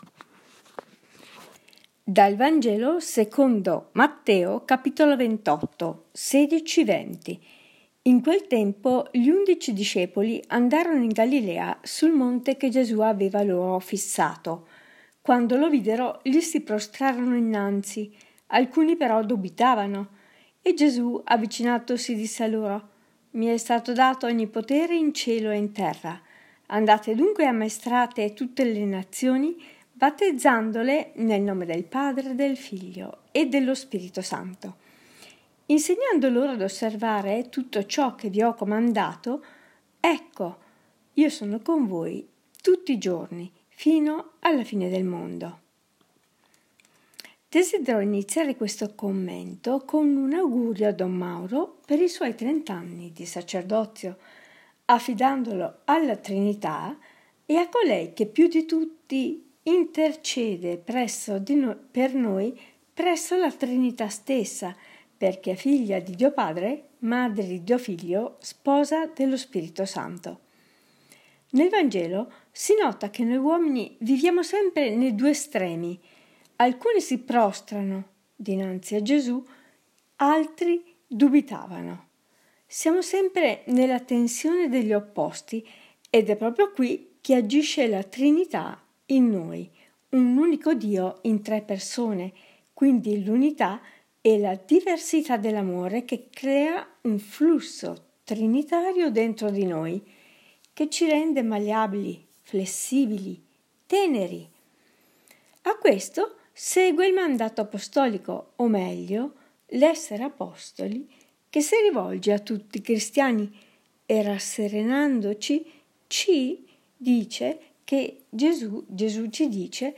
Commento al Vangelo (Mt 28,16-20) del 27 maggio 2018, Solennità della Ss.